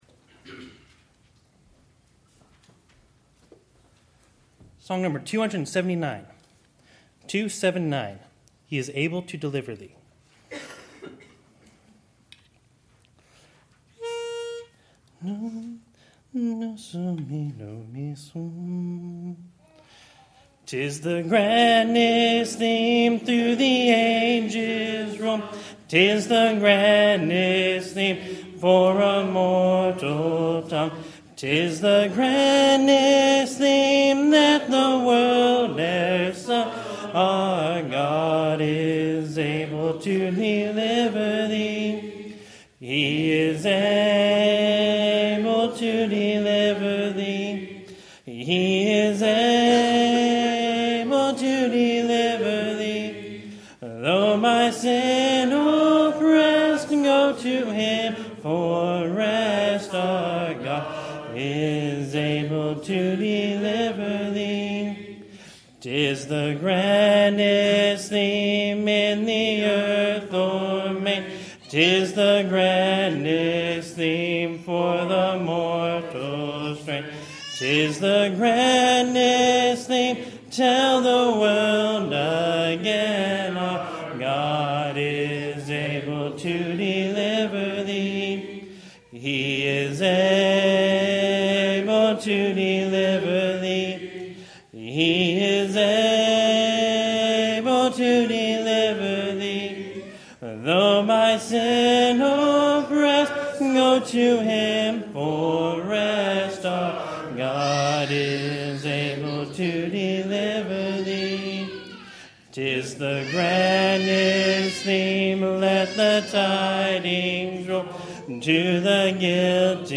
Song Service
Congregational Singing